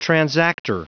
Prononciation du mot transactor en anglais (fichier audio)
Prononciation du mot : transactor